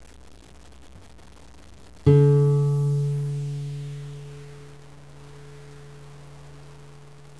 Tuning the Guitar
3. The third string sound like
string4.wav